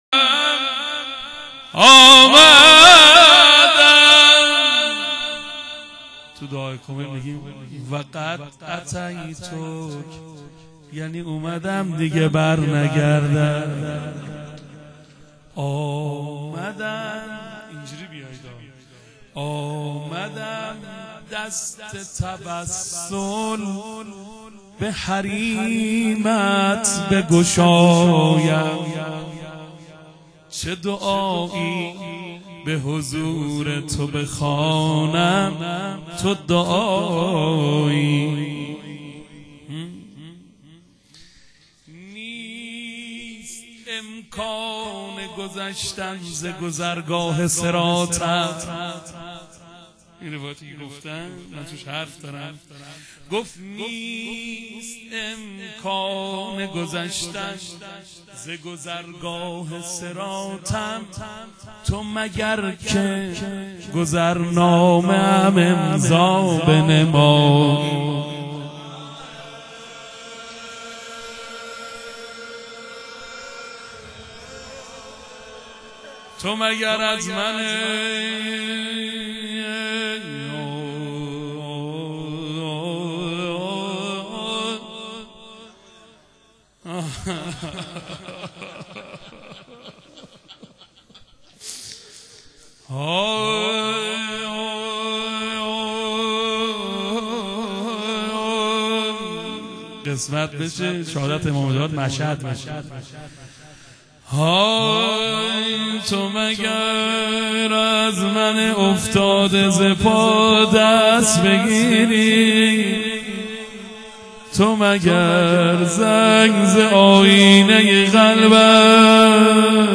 روضه.wma